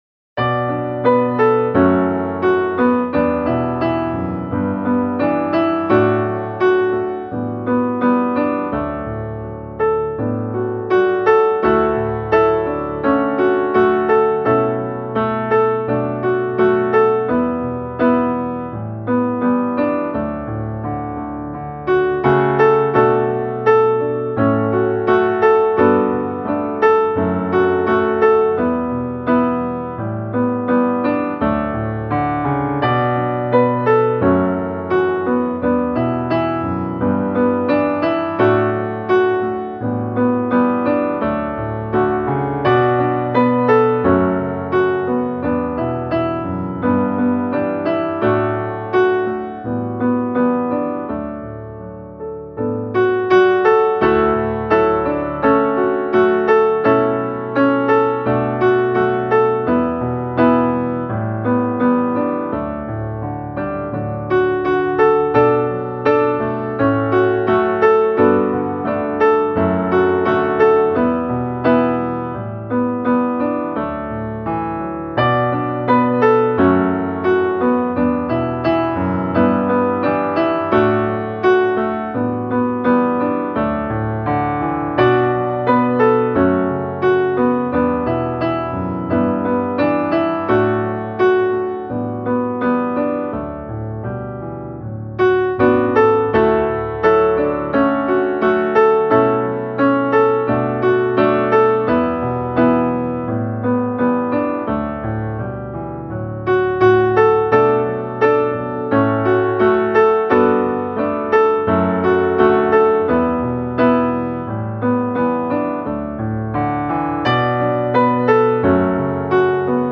Musikbakgrund Psalm